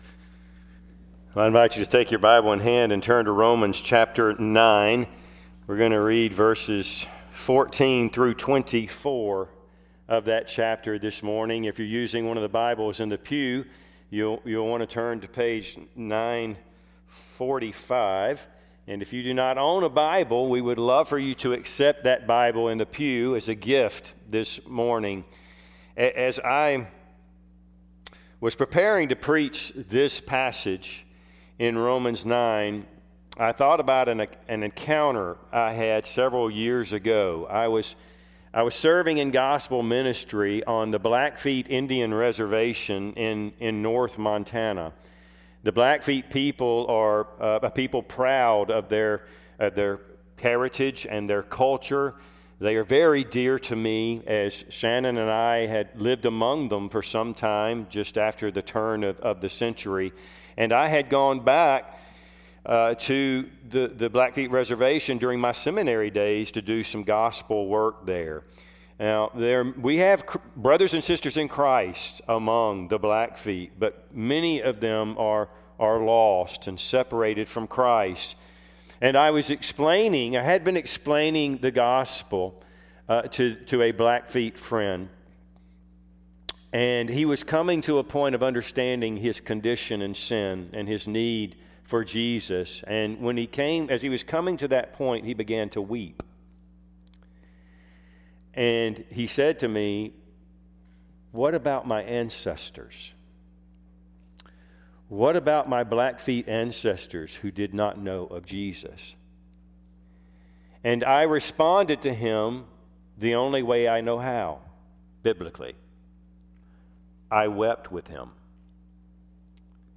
Service Type: Sunday AM Topics: Election , God's justice , God's mercy , God's Sovereignty